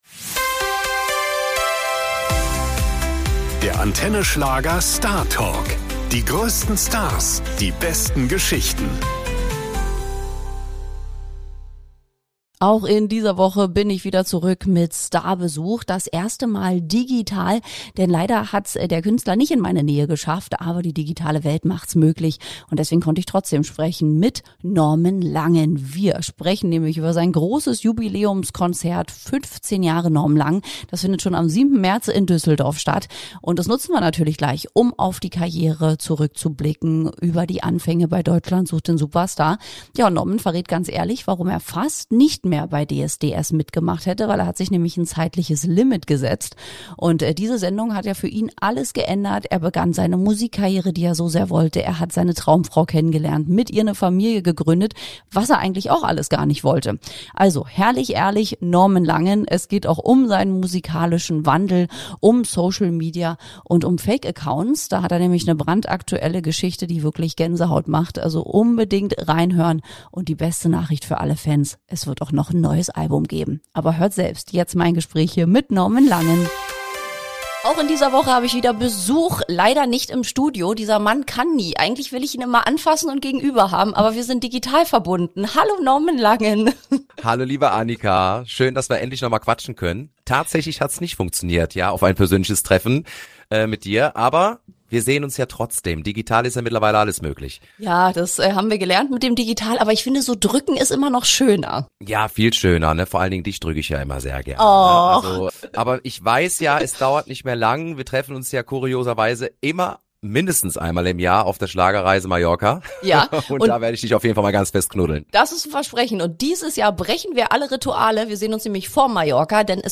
Im Gespräch erzählt der sympathische Sänger, wie sich sein Sound über die Jahre entwickelt hat – vom modernen Powerschlager über lateinamerikanische Einflüsse bis zu kraftvollen Partytracks – und was Fans von seinem neuen Album erwarten können.